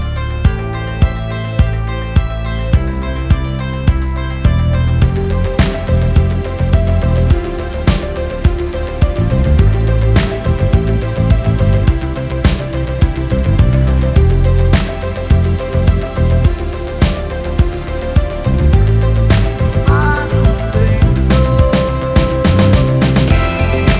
No Saxophone Pop (2000s) 4:37 Buy £1.50